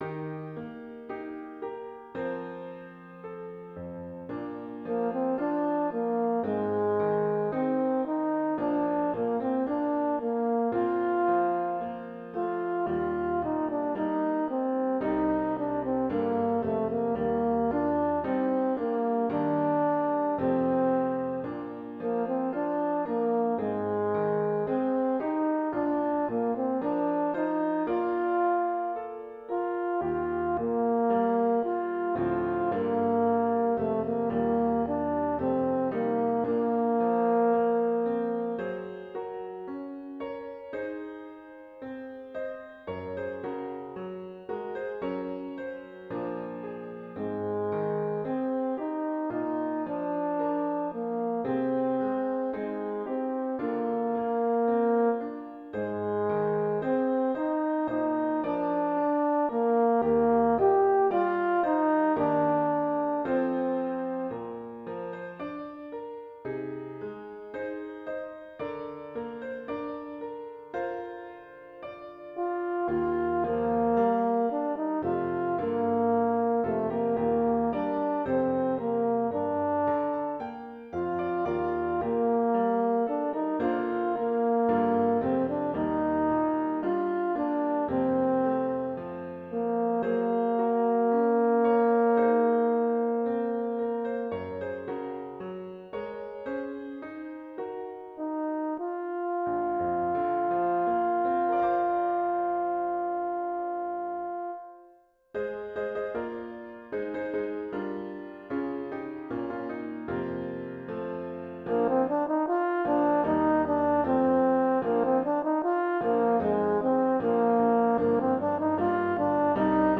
Voicing: French Horn and Piano